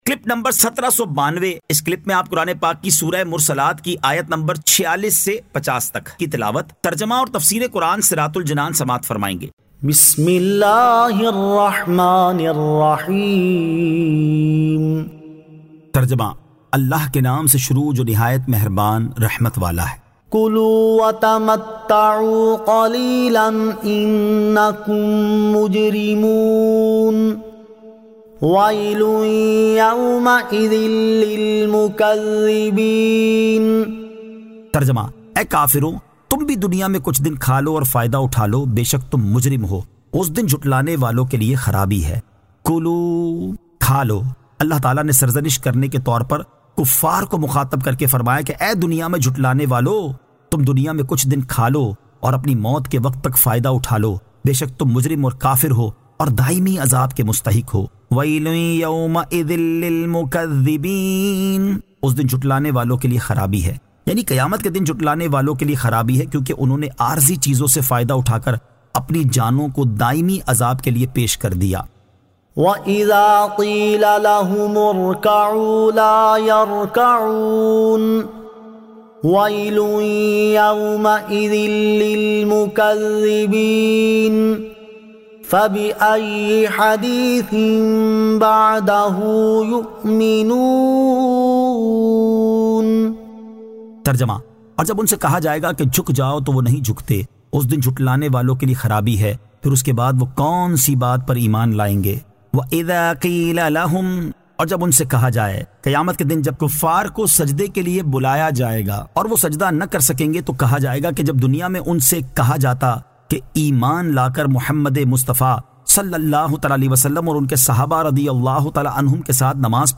Surah Al-Mursalat 46 To 50 Tilawat , Tarjama , Tafseer